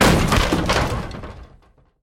Звуки выбивания двери
Спустили по лестнице, затем врезался в дверь всем телом и выбил ее